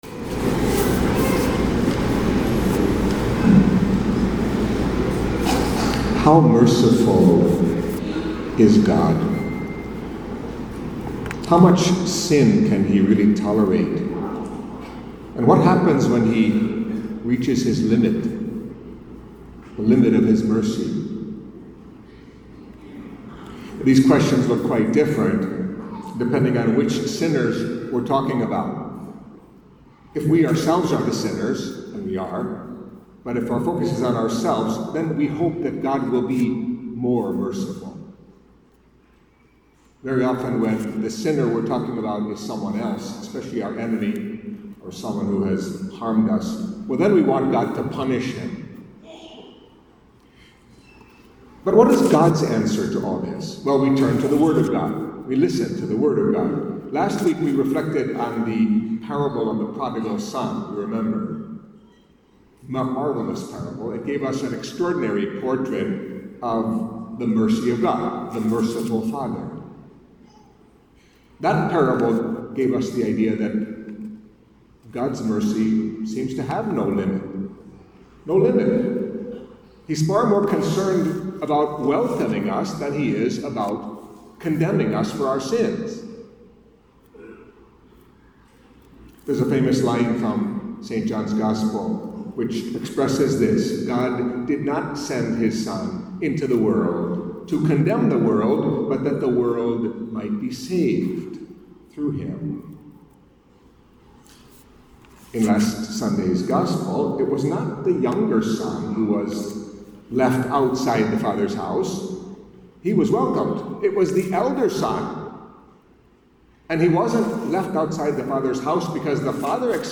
Catholic Mass homily for Fifth Sunday of Lent